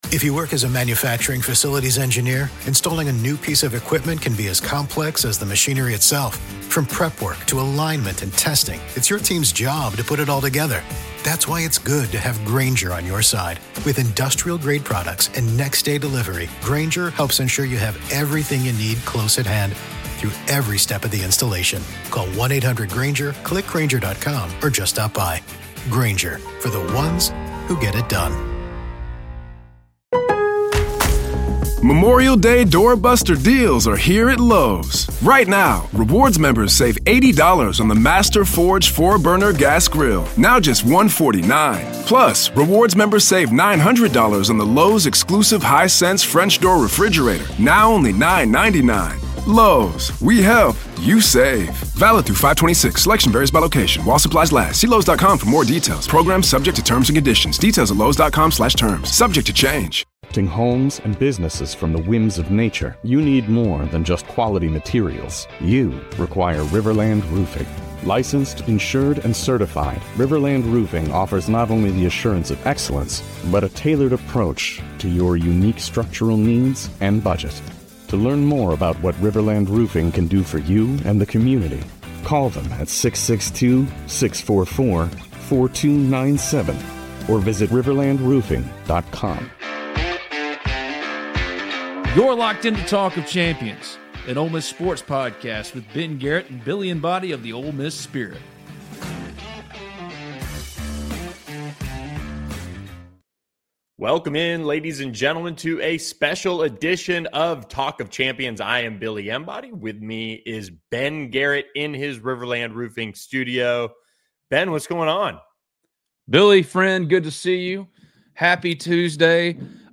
On this LIVE Talk of Champions